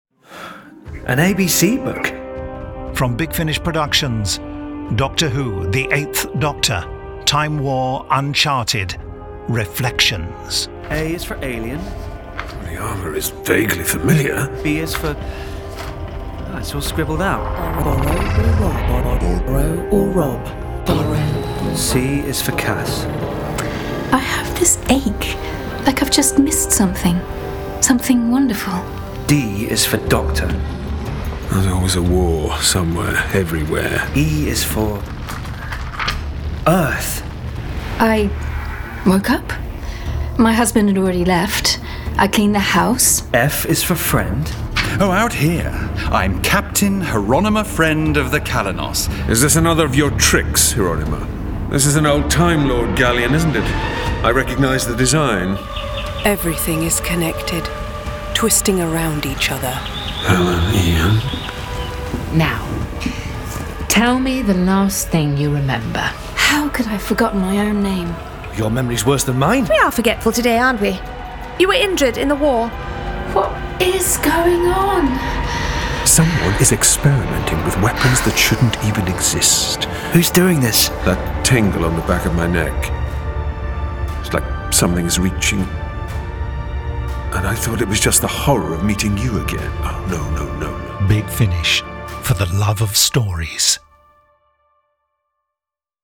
Starring Paul McGann